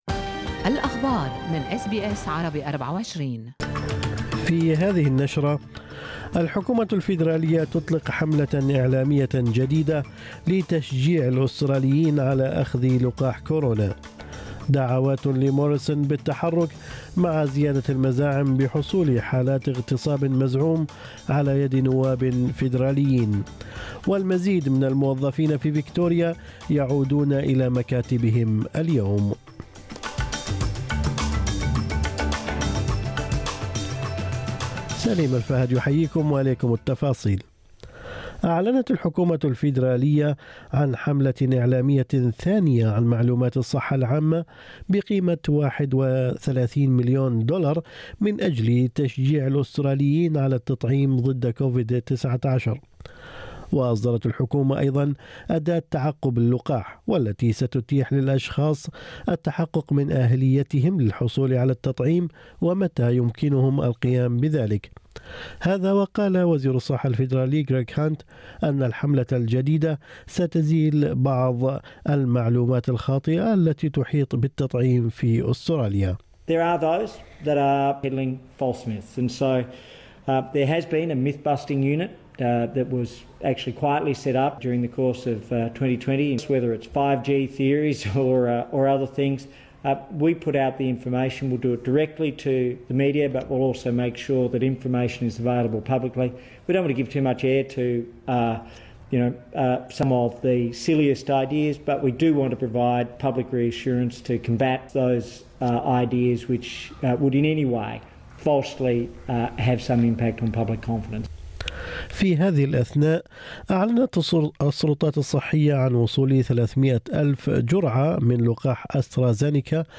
نشرة أخبار الصباح 1/3/2021